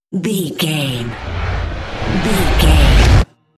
Trailer raiser flashback
Sound Effects
Fast paced
In-crescendo
Atonal
bouncy
bright
driving
futuristic
intense
dramatic
riser